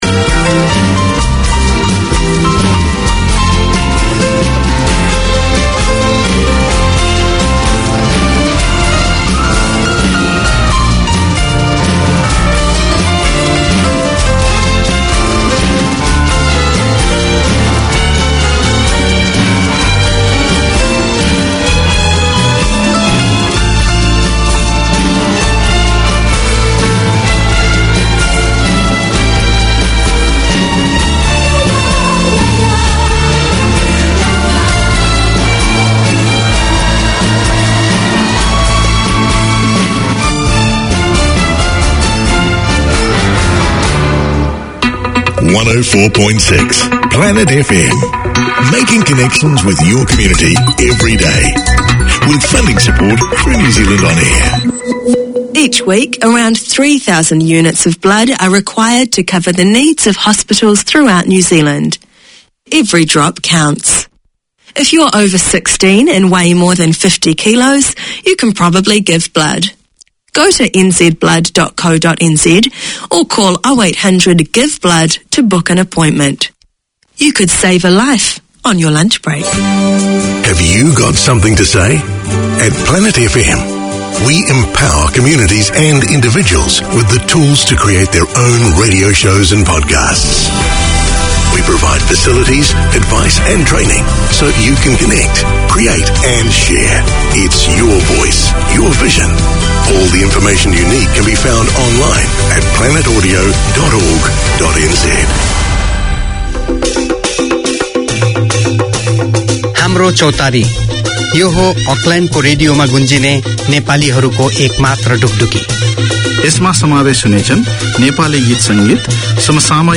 Te Ama Pasefika Health is promoting the well-being of Pacific people. Each week you’ll hear interviews with studio guests giving advice on health, education, employment and other support services that encourage wellness and foster healthy, happy lives for Pasefika people in New Zealand.